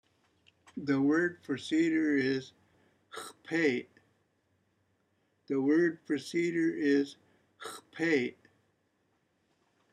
pronunciation of Xpey'